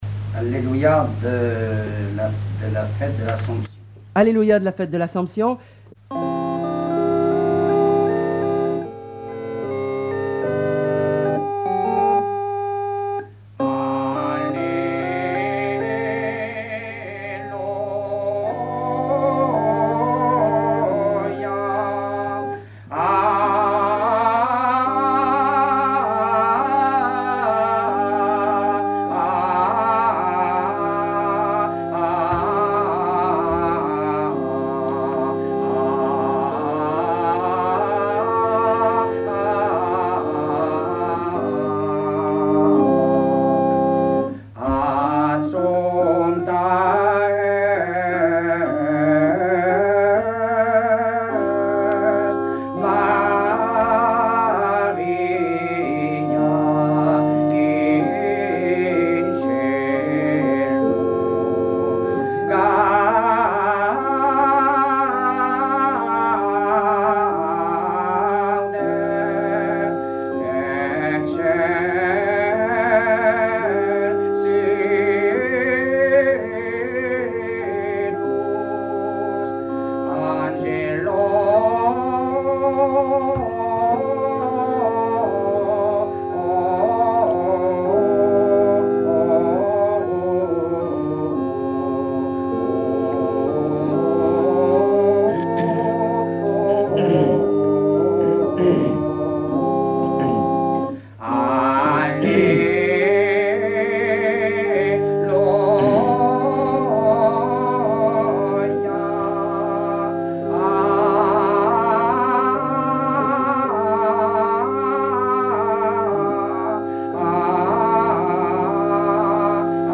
Genre strophique
numérisation d'émissions
Pièce musicale inédite